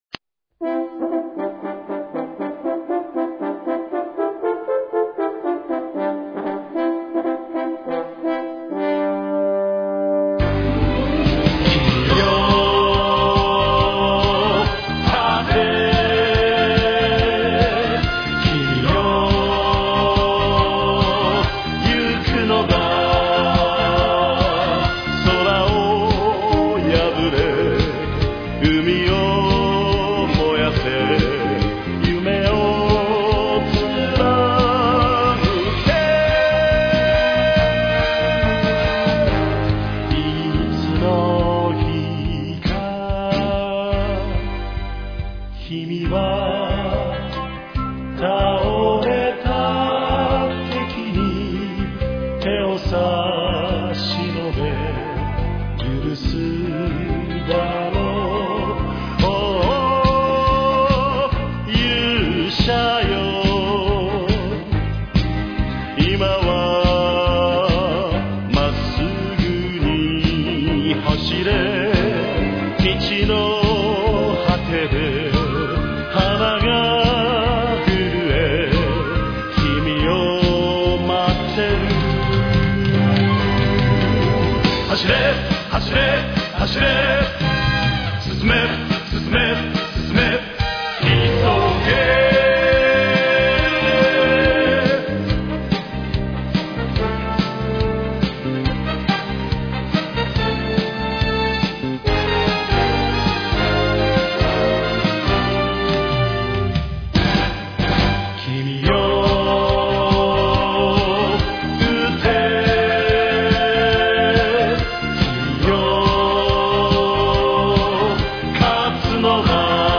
Tema de Apertura.
Original, version completa en japones.